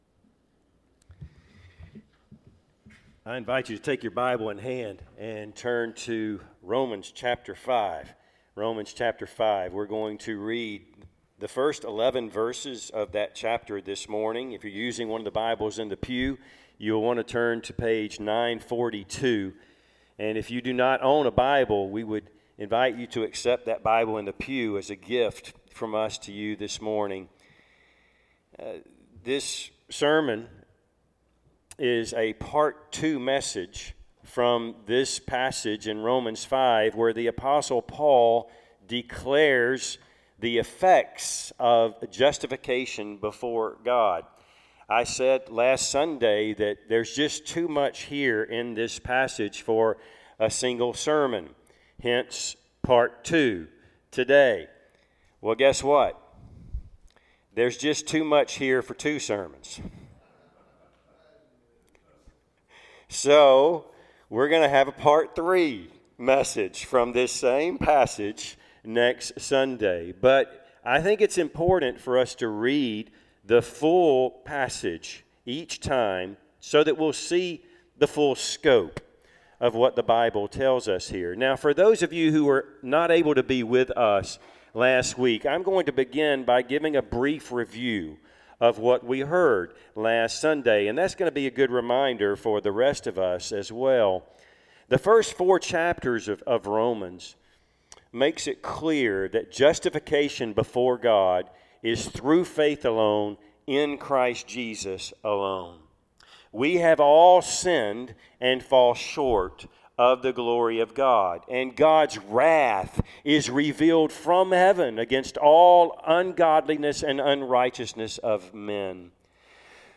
Passage: Romans 5:1-11 Service Type: Sunday AM